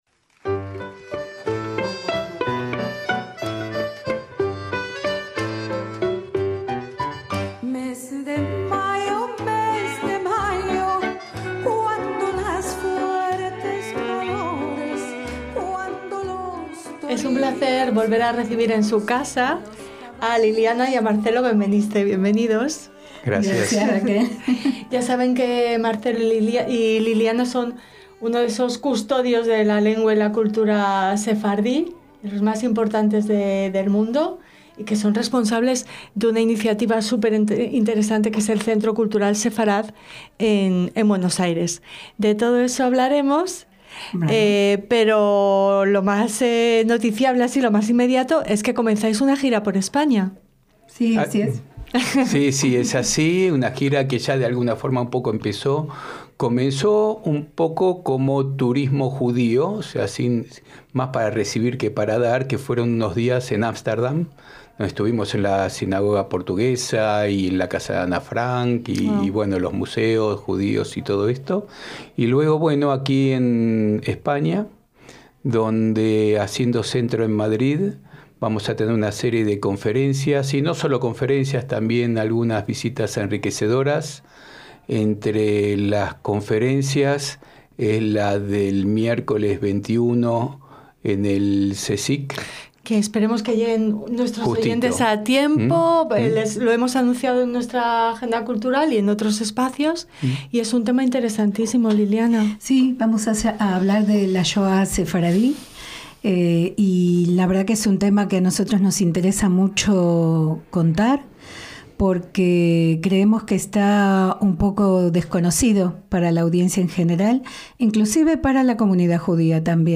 A FONDO - Recibimos la visita -en Radio Sefarad y en Sefarad, su casa-